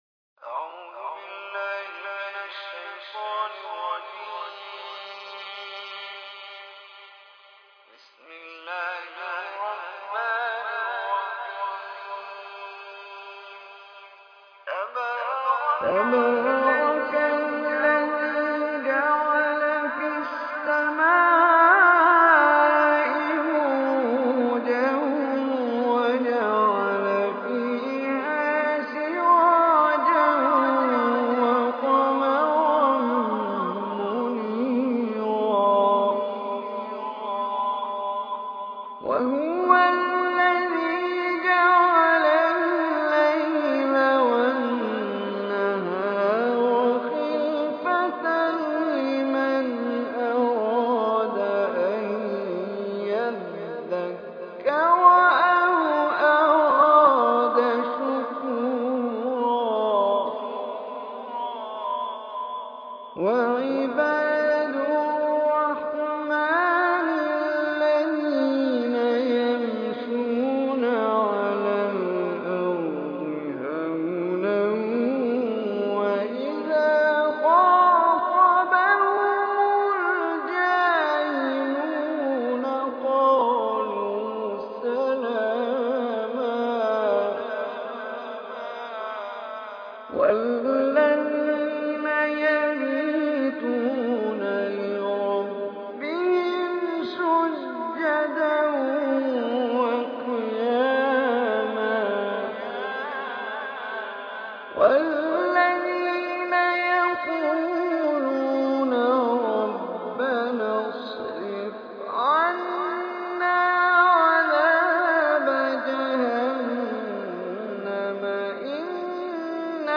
Surah Furqan is 25 Surah of Holy Quran. Listen or play online mp3 tilawat / recitation in arabic in the beautiful voice